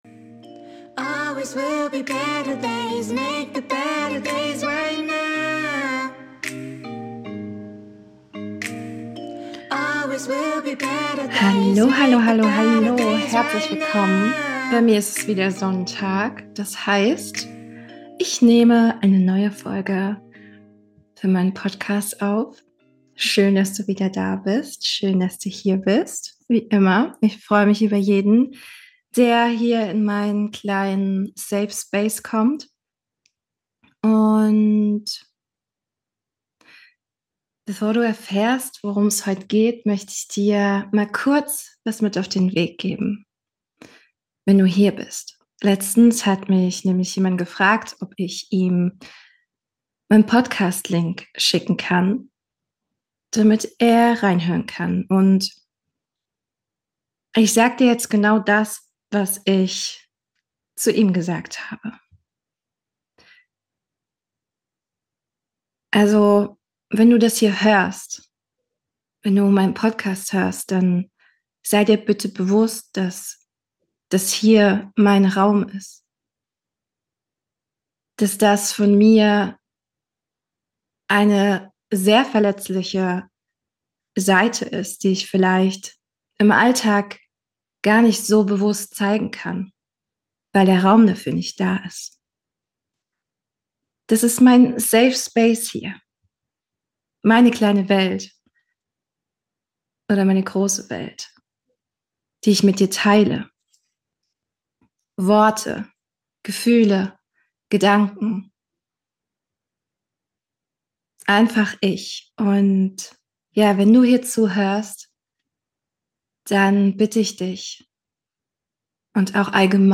#28 Spoken Words ~ Soul.Mind.Waves Podcast